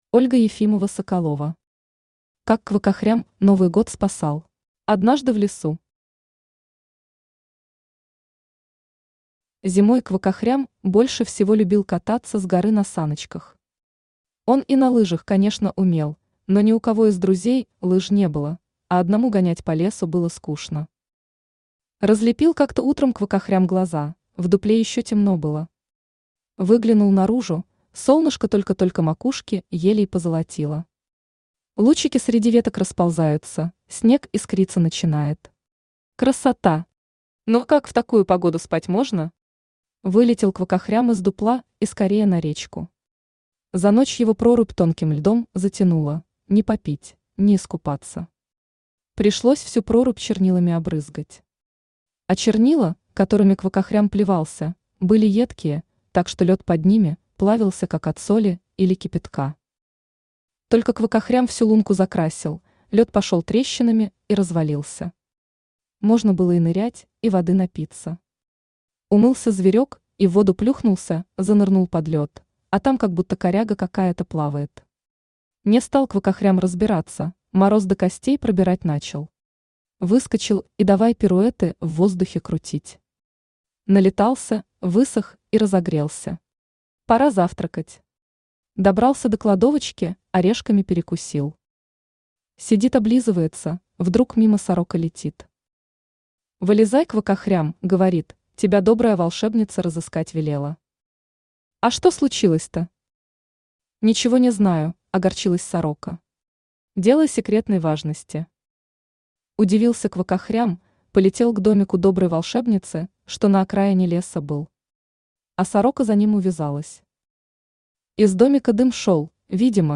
Aудиокнига Как Квакахрям Новый Год спасал Автор Ольга Ефимова-Соколова Читает аудиокнигу Авточтец ЛитРес.